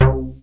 DEEP C4.wav